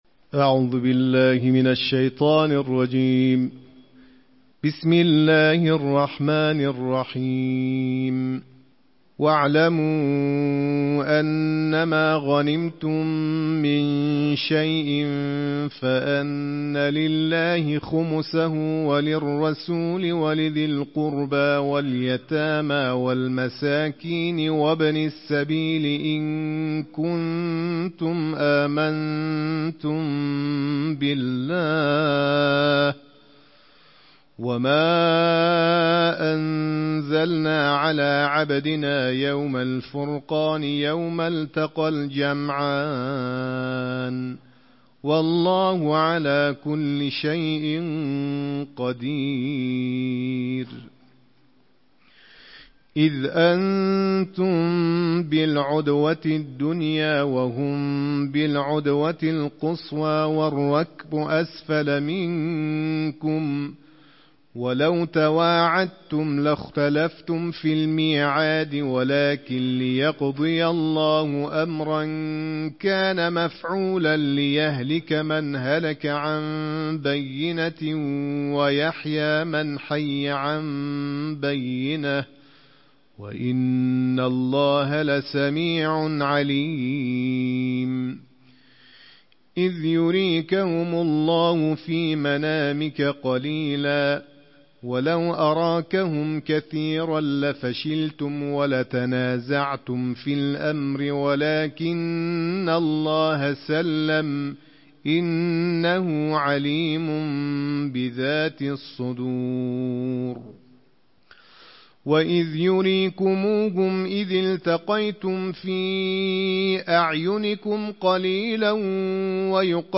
अंतर्राष्ट्रीय क़ारियों की आवाज़ों के साथ कुरान के दसवें जुज़ का पाठ + ऑडियो